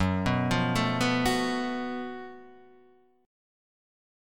F# Minor Major 7th Double Flat 5th